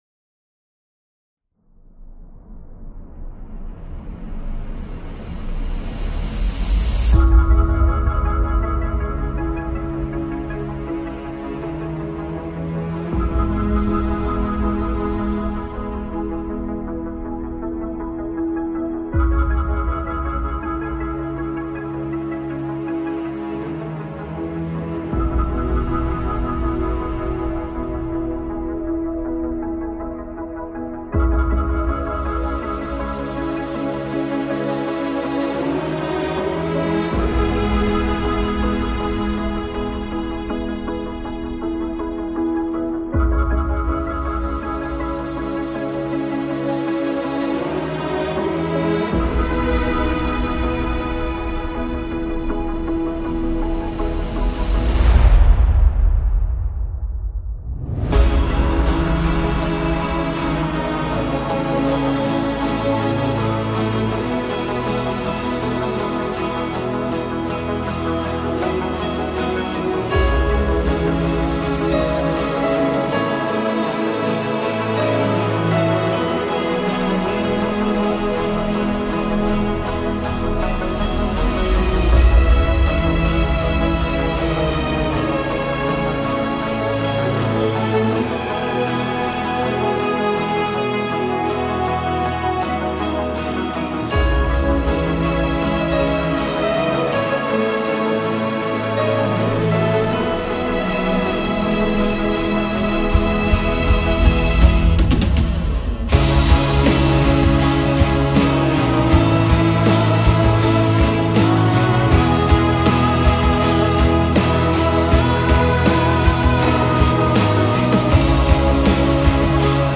آهنگ حماسی زیبا به بهانه 28 ساله شدن مسی